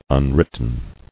[un·writ·ten]